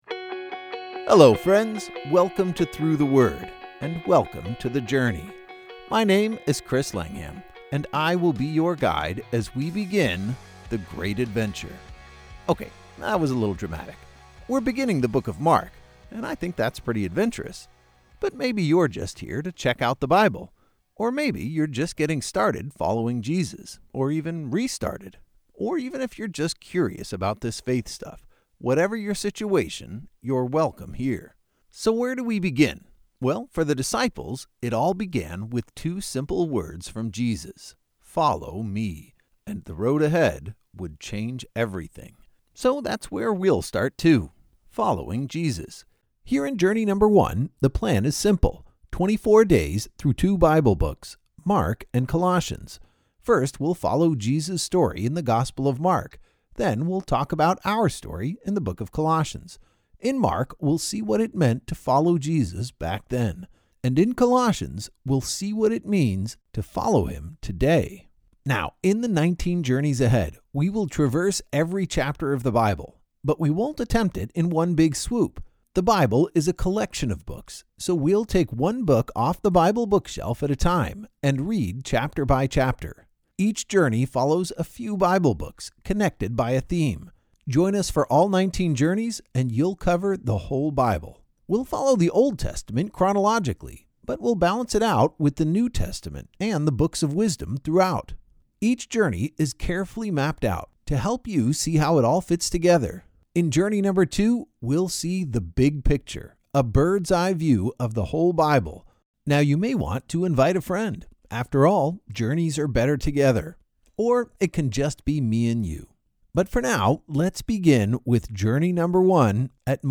Each journey is an epic adventure through several Bible books, as your favorite pastors explain each chapter in a friendly and compelling audio guide.